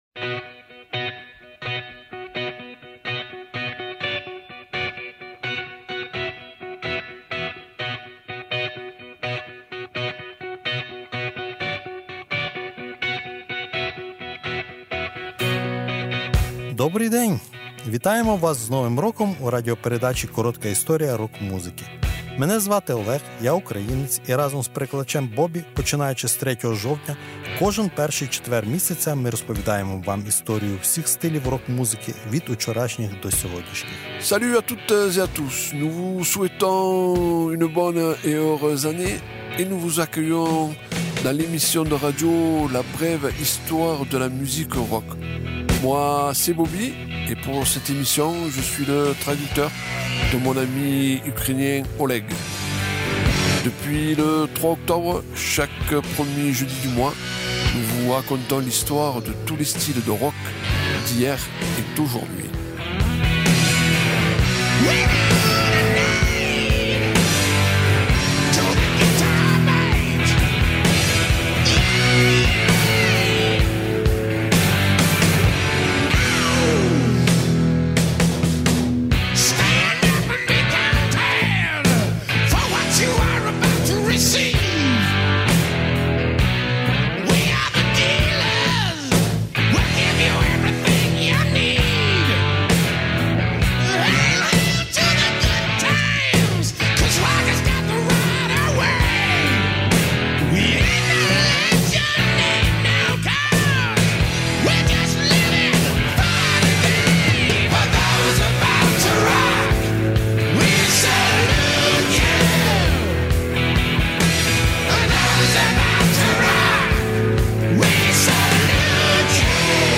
Spéciale musique blues Rock